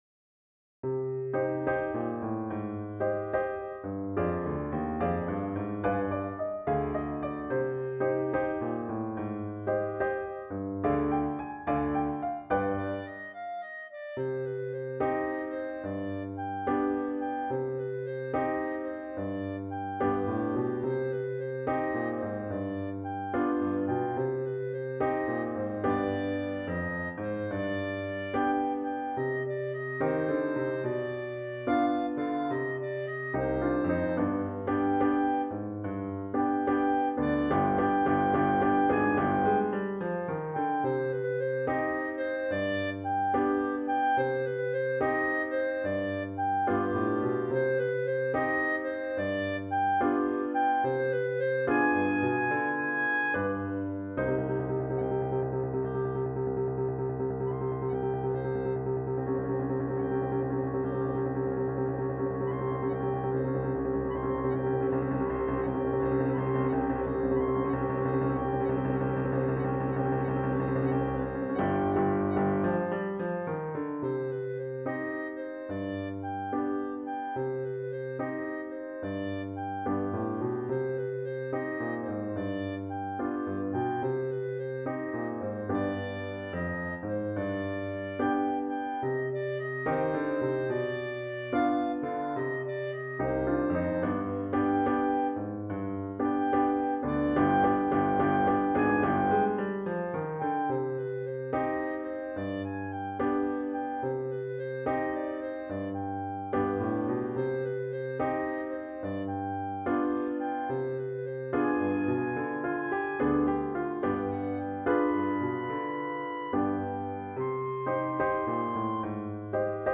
clarinet piano swing